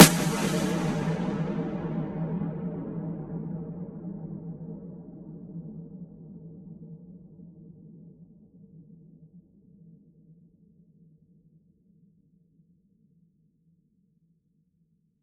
forced-triumph-impact-snares_C_major.wav